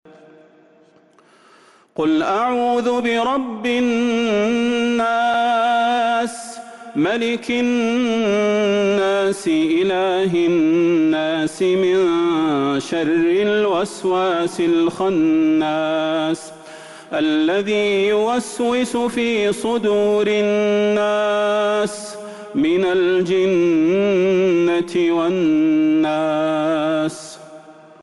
سورة الناس Surat An-Nas من تراويح المسجد النبوي 1442هـ > مصحف تراويح الحرم النبوي عام 1442هـ > المصحف - تلاوات الحرمين